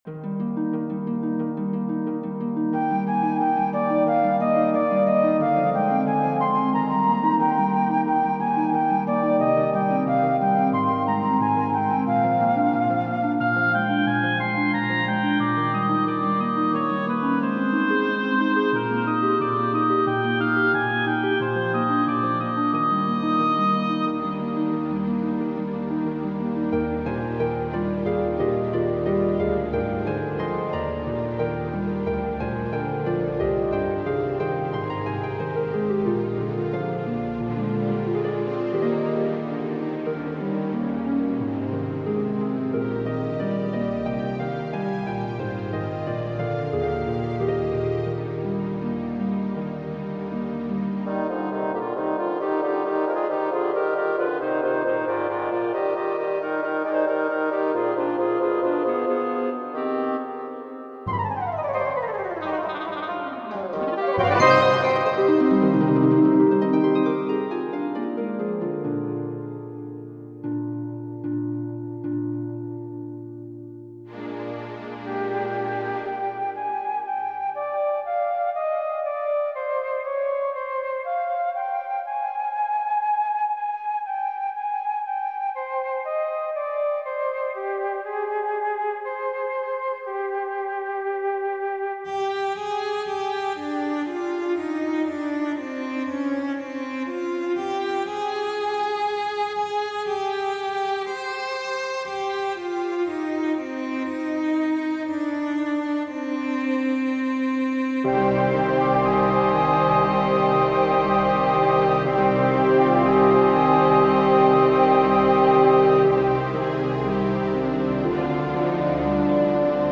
Nocturne Op. 2 - Orchestral and Large Ensemble